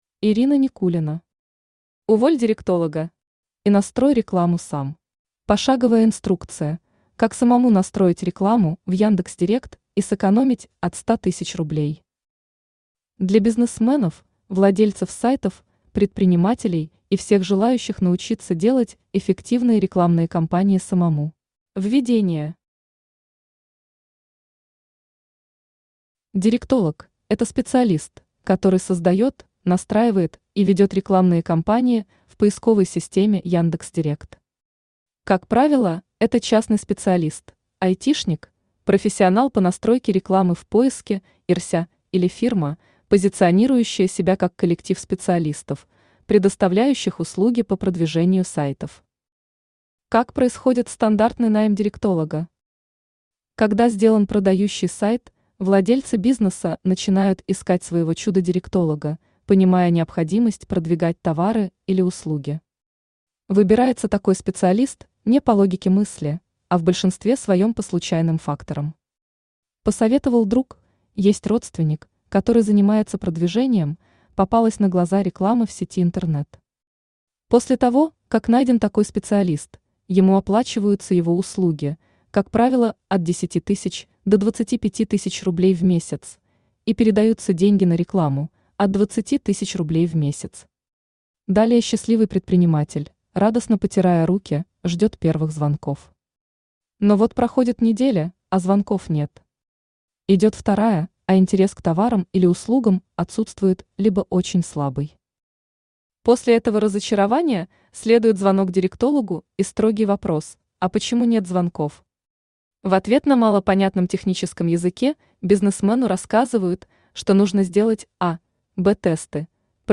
Aудиокнига Уволь директолога! и настрой рекламу сам Автор Ирина Никулина Читает аудиокнигу Авточтец ЛитРес.